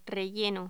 Locución: Relleno
voz
Sonidos: Voz humana